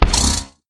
sounds / mob / horse / skeleton / hit3.mp3